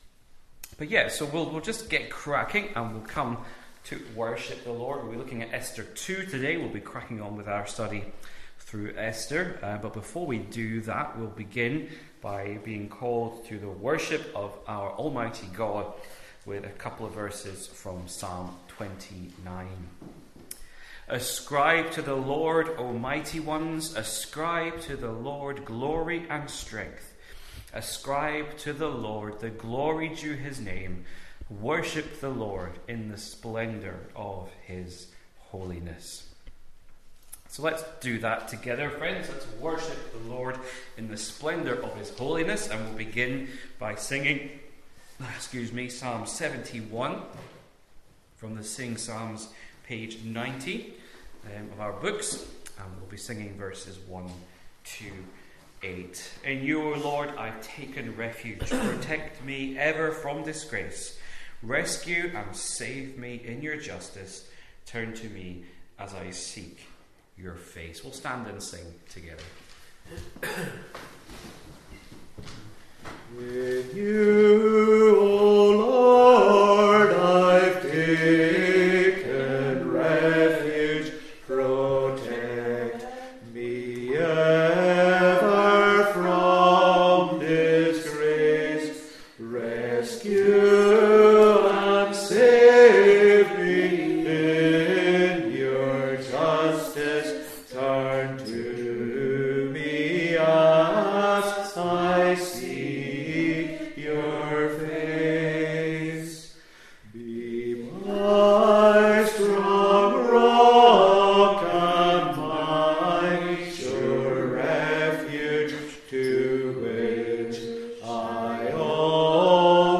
Esther Passage: Esther 2:1-23 Service Type: Glenelg PM « The Poor in Spirit are Truly Rich!
6.00pm-Service.mp3